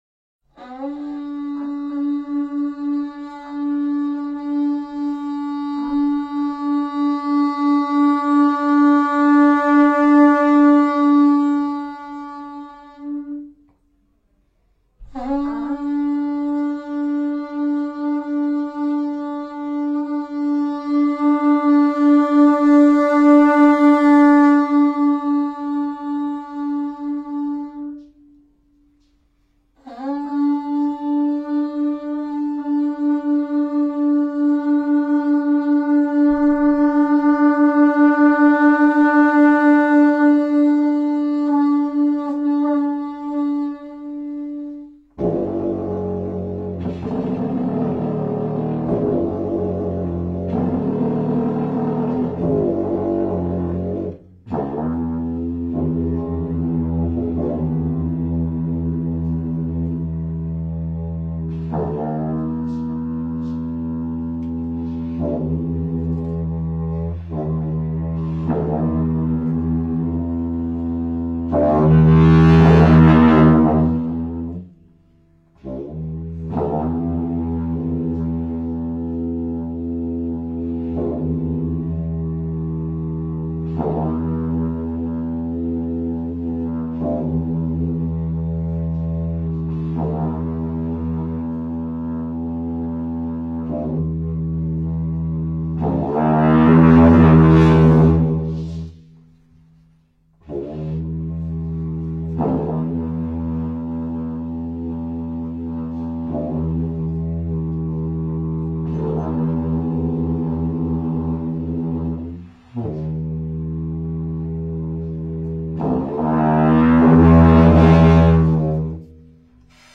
佛音 诵经 佛教音乐 返回列表 上一篇： 峨嵋佛光 下一篇： 忿怒莲师冈梭(二